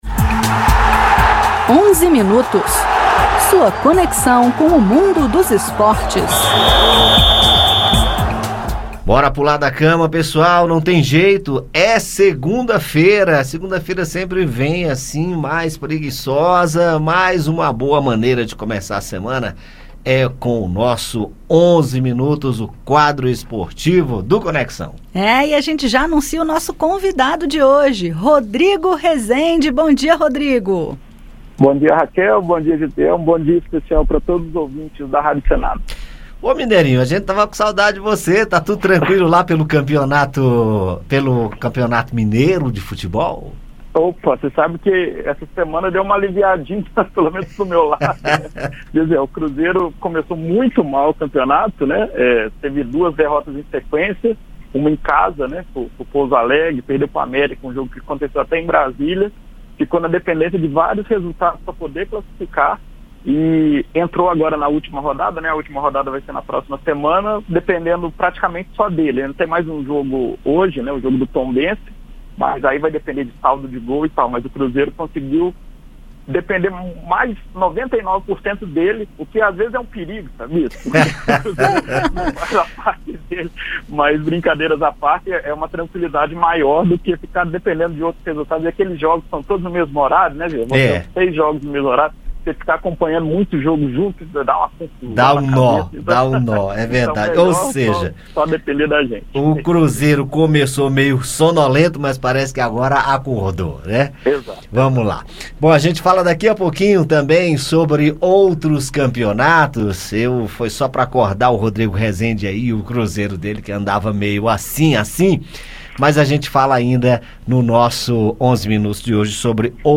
A edição também traz os comentários esportivos da semana